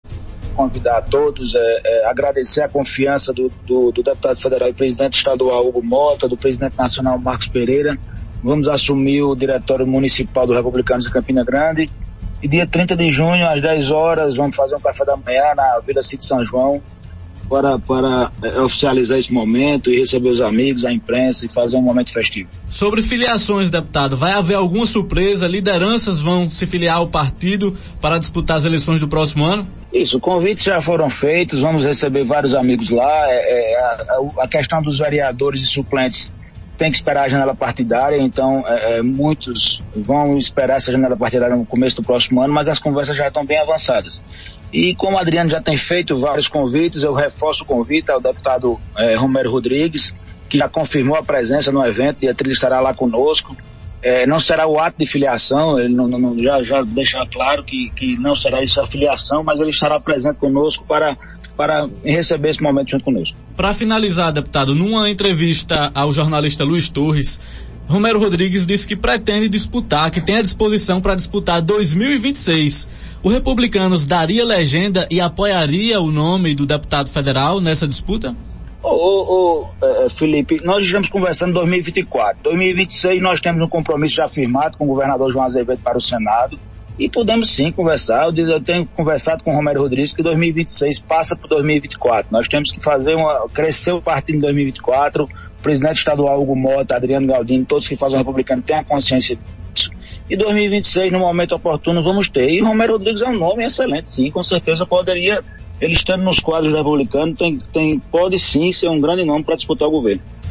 O deputado Murilo Galdino, em entrevista nesta sexta-feira (16), ressaltou, que o convite para que Romero se filie à legenda segue de pé. Ele ainda disse que Romero é um nome forte a ser levado em consideração na disputa de 2026 caso esteja nos quadros do partido.
As declarações repercutiram no programa Arapuan Verdade